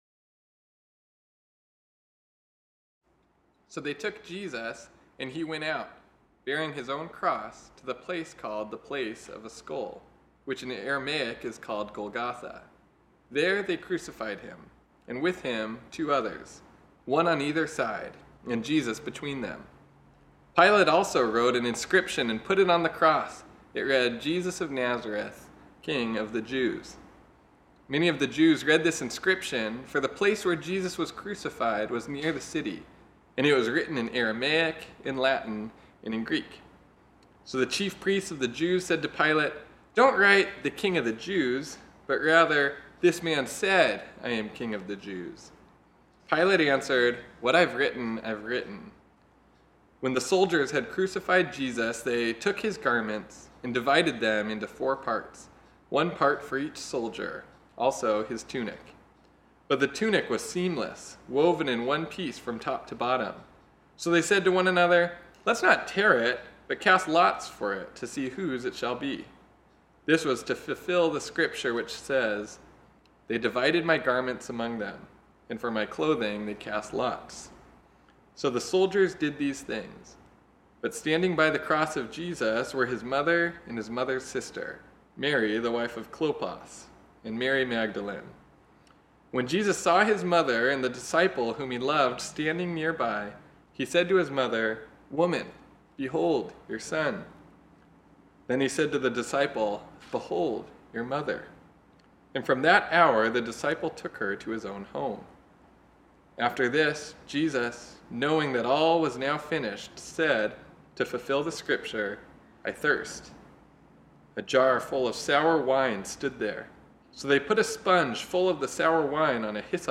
This sermon was originally preached on Sunday, August 2, 2020.